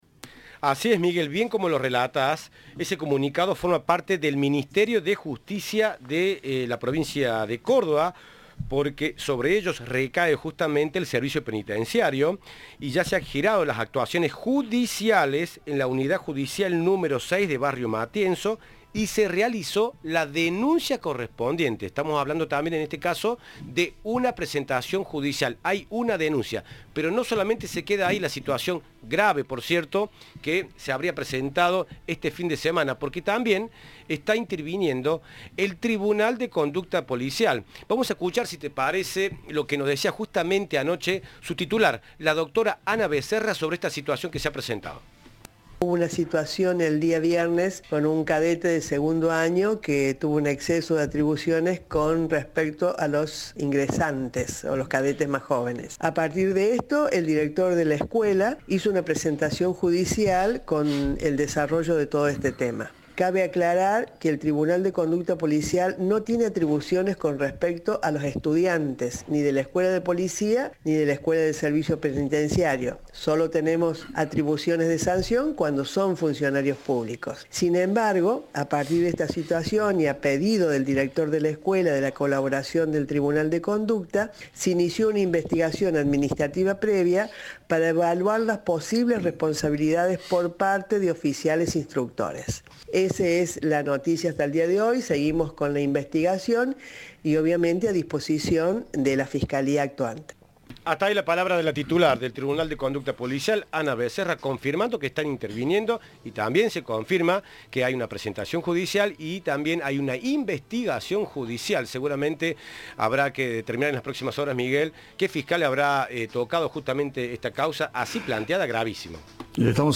Al respecto, Ana Becerra titular del Tribunal de Conducta Policial, detalló los hechos y dijo a Cadena 3 que "un cadete de segundo año tuvo un exceso de atribuciones con respecto a los ingresantes o cadetes más jóvenes".
Informe